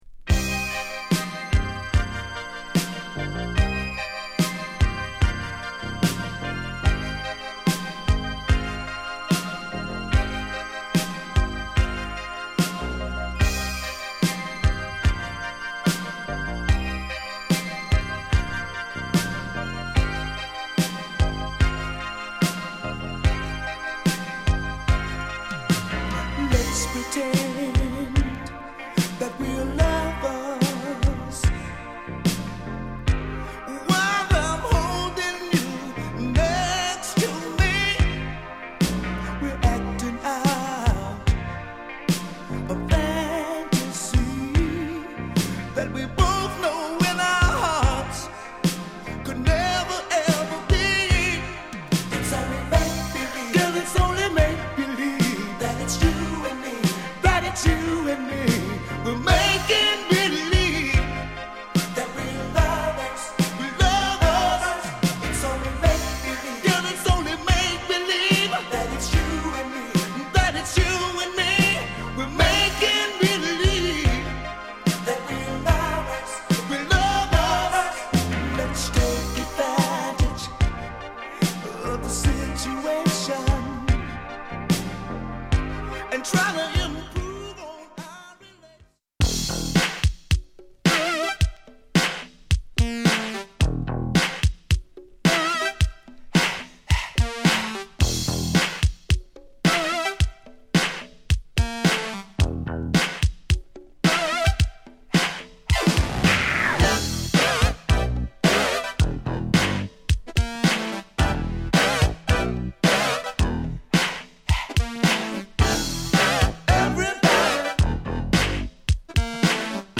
グルーヴィ DISCO FUNK
モダンファンク